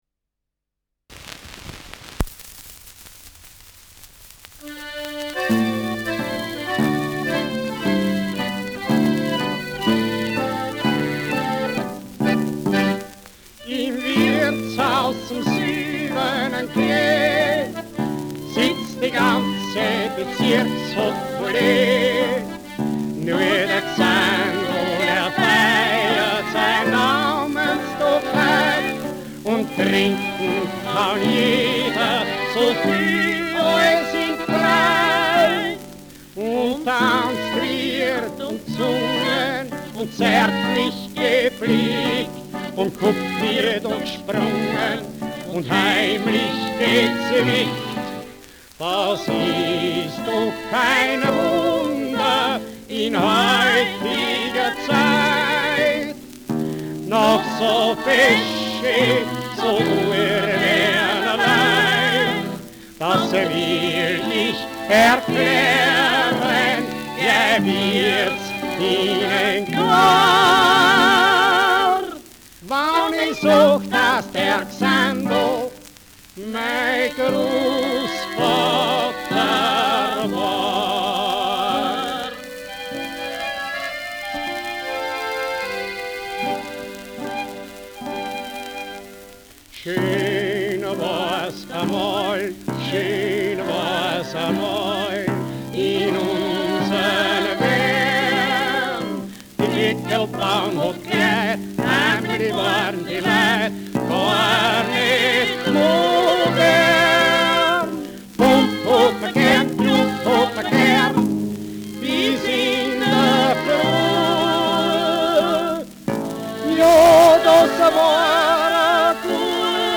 Schellackplatte
[Wien] (Aufnahmeort)